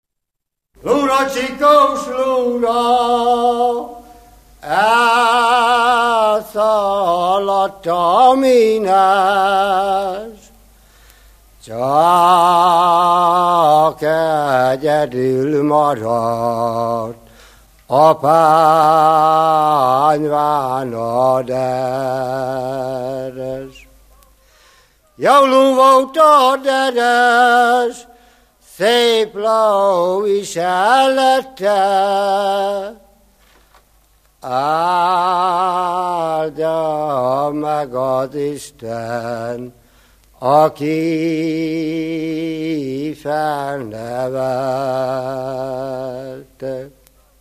Alföld - Bihar vm. - Létavértes
ének
Stílus: 1.1. Ereszkedő kvintváltó pentaton dallamok
Szótagszám: 6.6.6.6